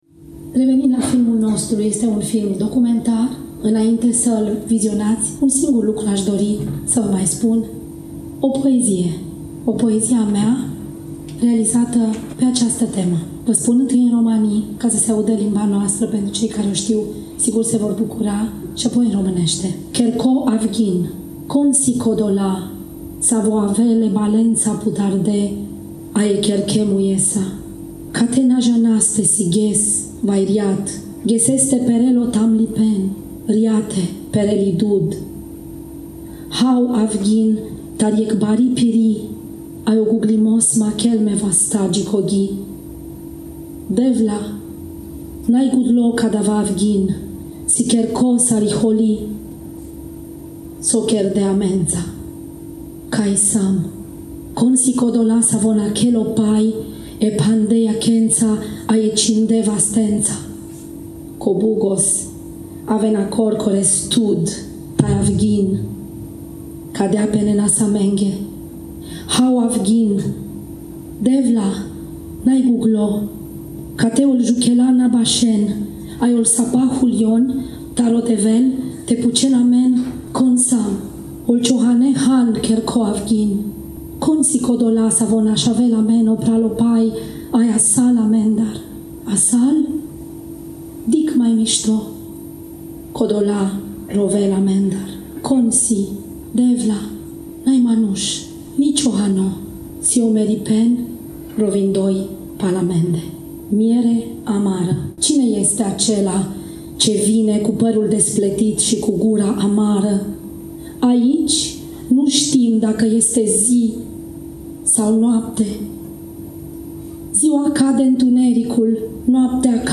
Poezia a fost recitată înainte de rularea filmului documentar De ce nu plângem? Subiectul poemului este în strânsă legătură cu tema filmului documentar.